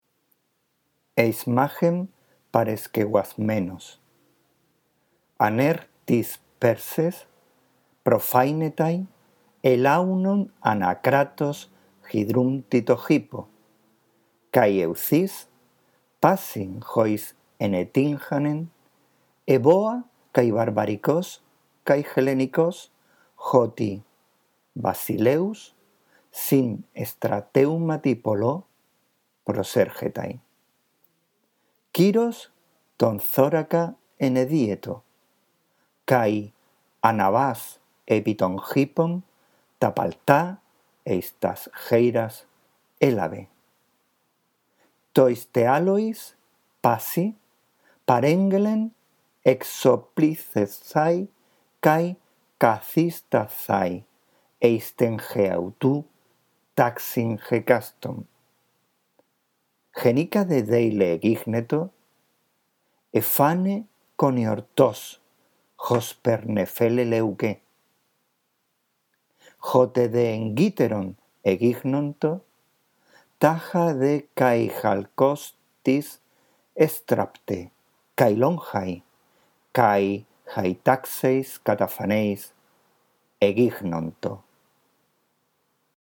La audición de este archivo te ayudará en la práctica de la lectura del griego: